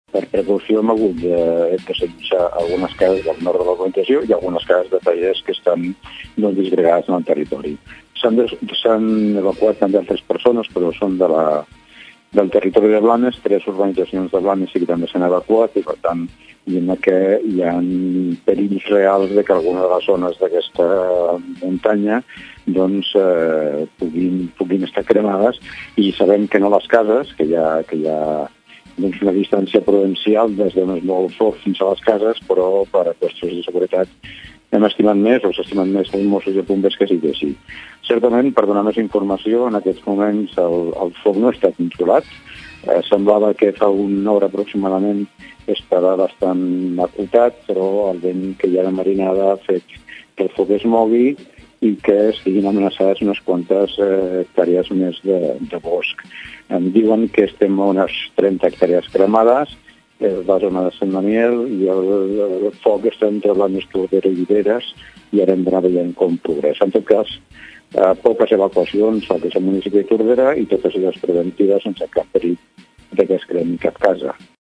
En declaracions a Ràdio Tordera, l’Alcalde del nostre municipi diu que els veïns de Sant Daniel desallotjats no han de patir per les seves cases.
alcalde-incendi-blanes-1.mp3